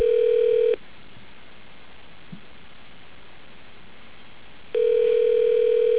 Phone System Features - Tones
ring.au